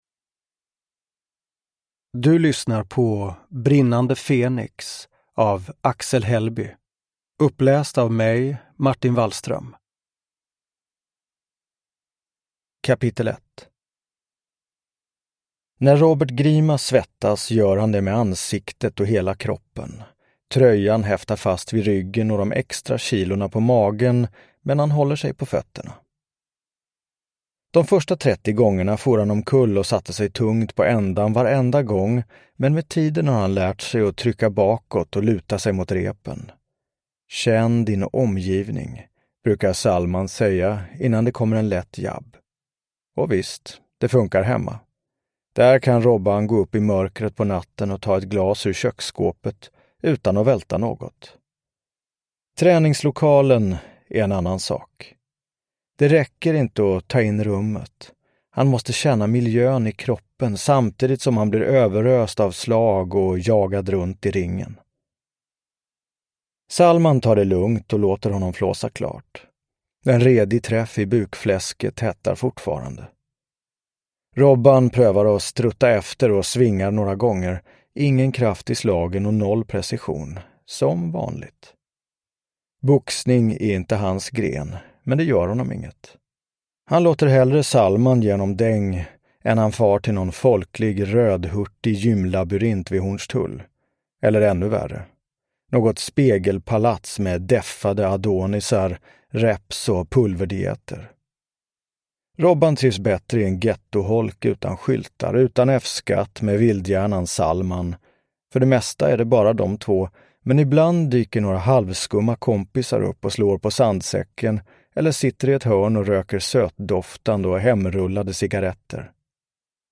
Uppläsare: Martin Wallström
Ljudbok